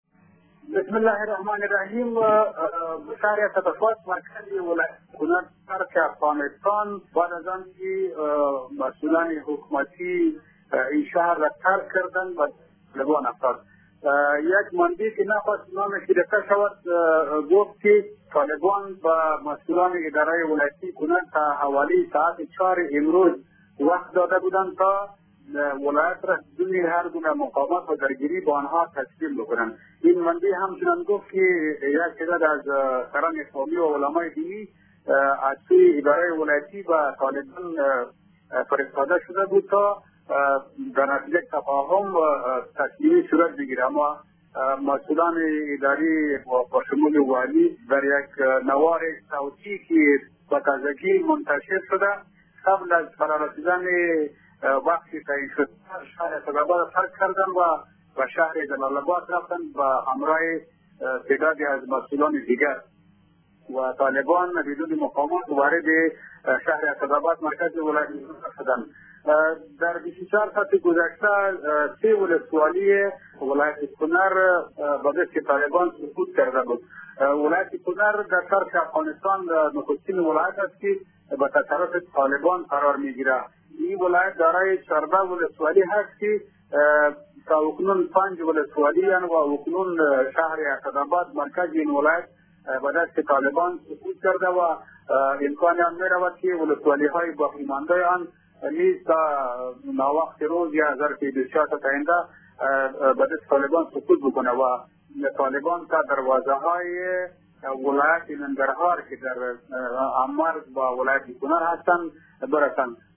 گزارش خبرنگار رادیو دری ازآخرین وضعیت ولایت کنر و ورود گروه طالبان به مرکز این ولایت و آخرین تحولات منطقه